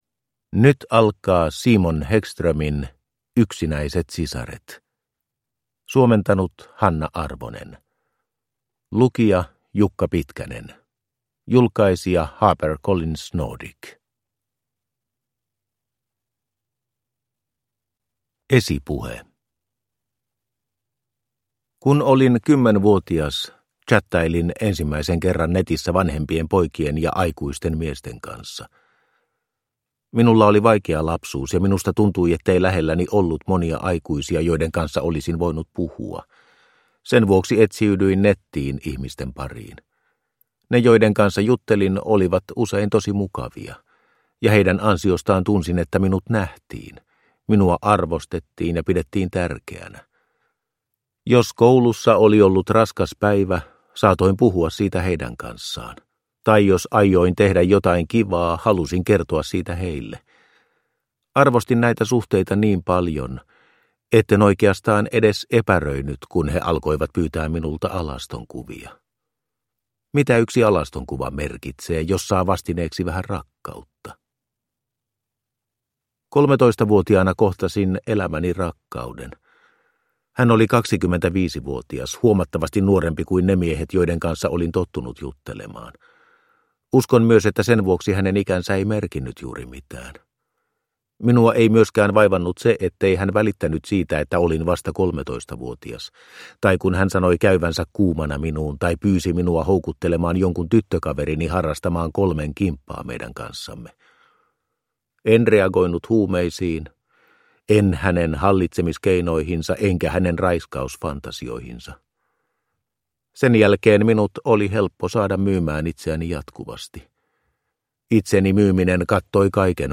Yksinäiset sisaret – Ljudbok